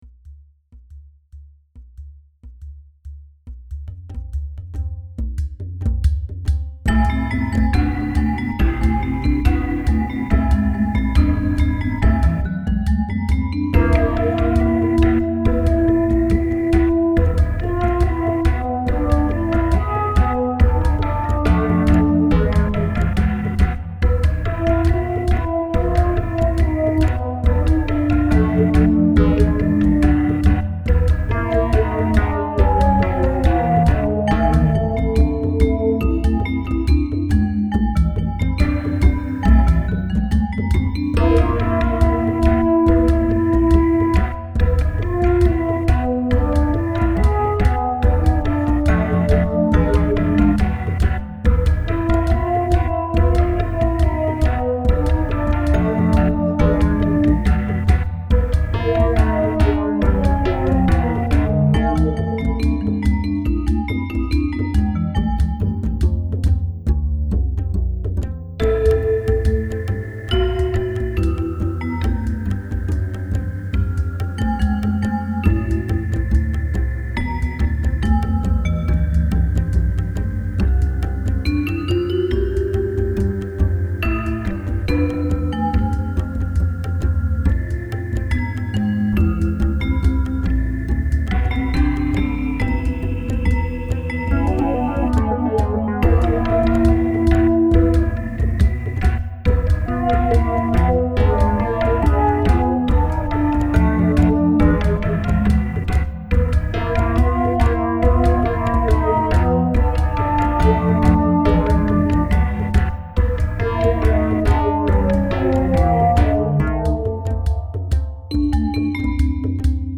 Funk/Jazz/Blues/Reggae
Here are more light-hearted songs in a mélange of styles.